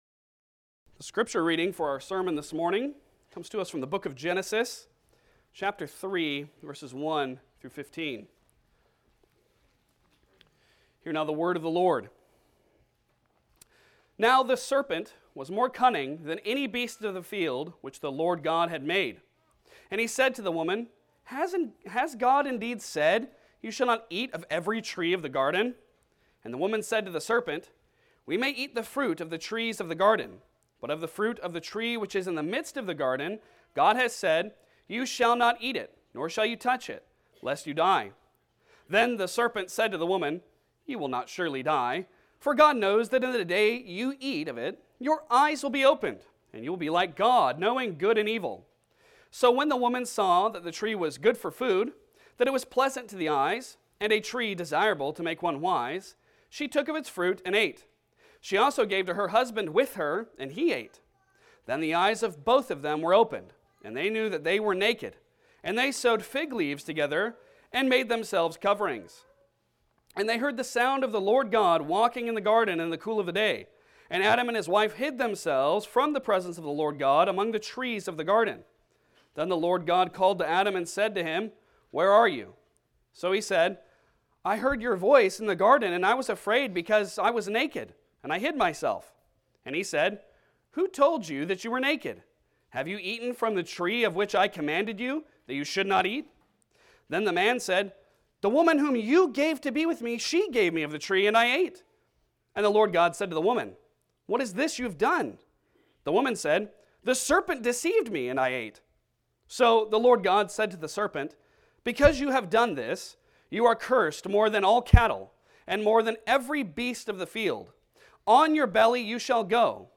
Advent 2025 Passage: Genesis 3:1-15 Service Type: Sunday Sermon Download Files Bulletin Topics